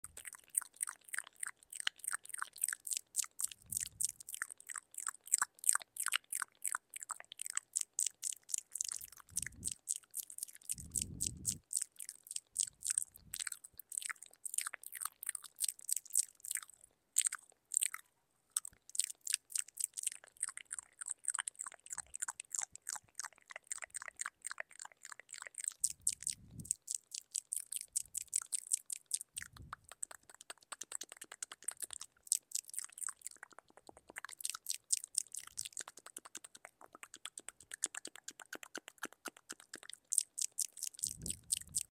Can You Hear The Rain Sound Effects Free Download